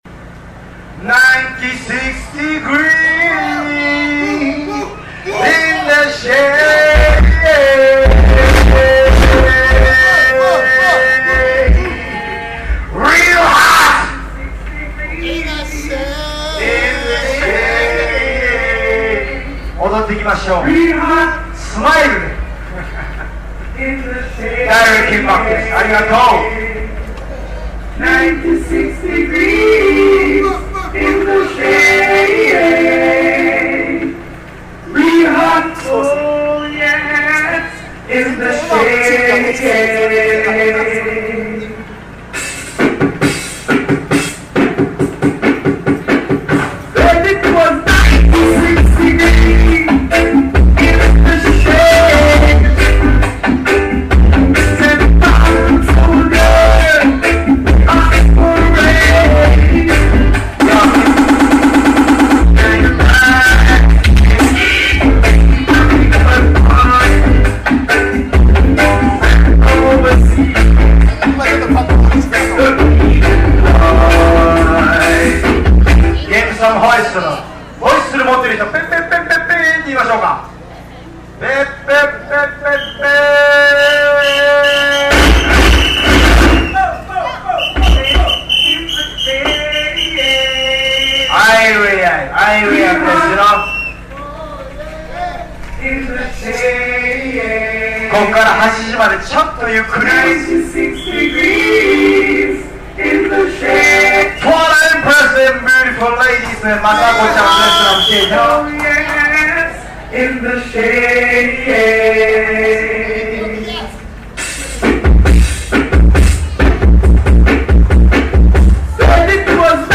DI 20thAnniversaly ROOTS REGGAE CARNIVAL in Japan